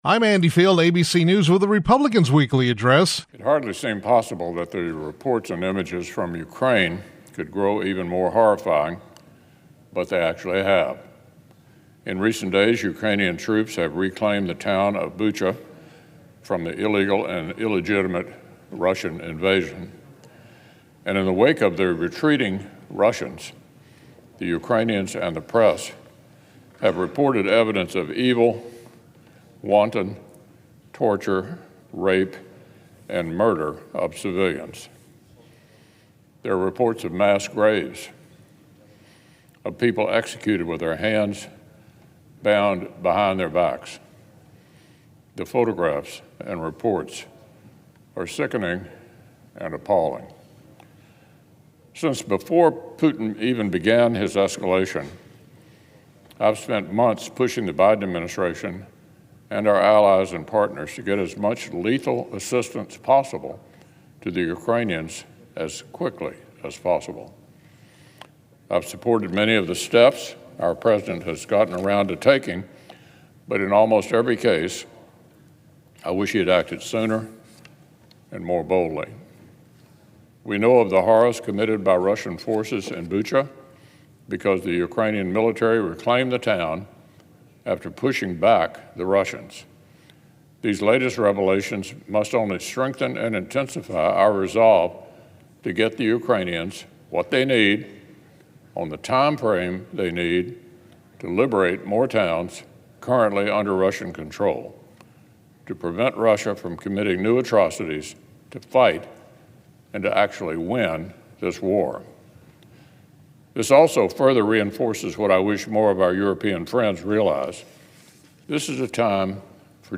U.S. Senate Republican Leader Mitch McConnell (R-KY) delivered remarks Monday on the Senate floor regarding Ukraine.